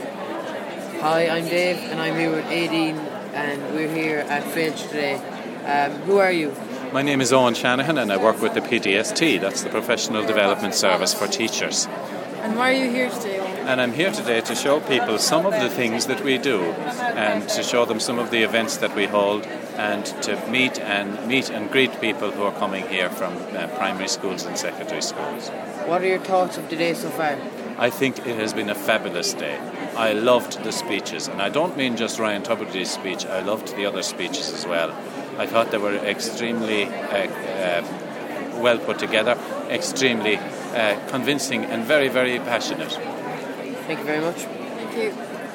Talking about teaching and learning with the experts at Ireland's largest teachers' conference.